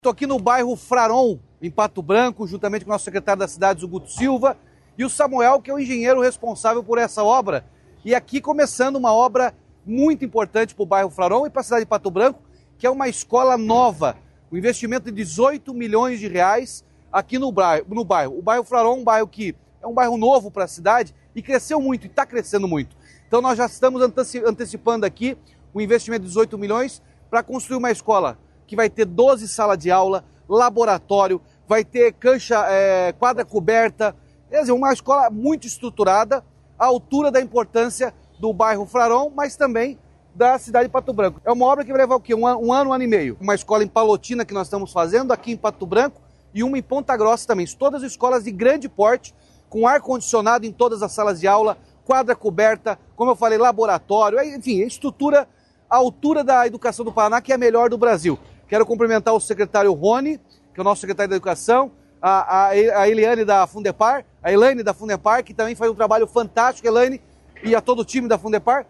Sonora do governador Ratinho Junior sobre a obra de uma nova escola estadual no bairro Fraron, em Pato Branco